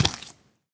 step4.ogg